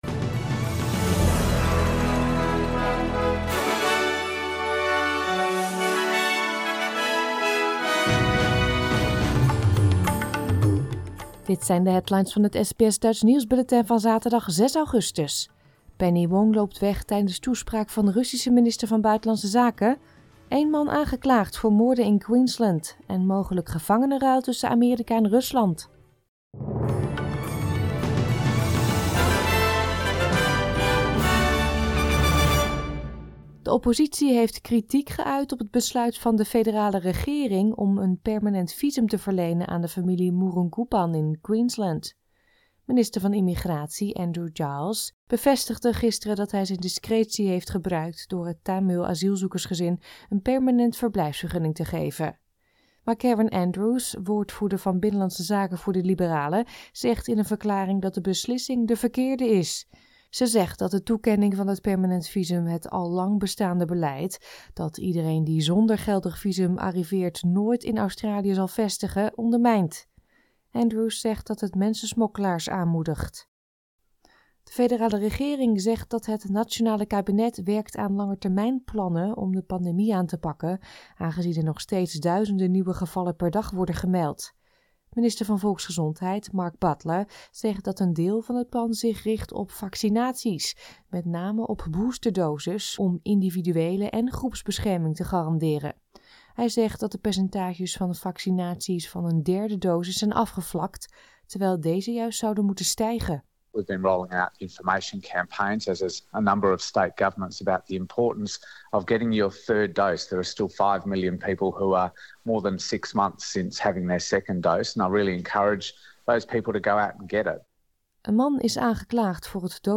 Nederlands / Australisch SBS Dutch nieuwsbulletin van zaterdag 6 augustus 2022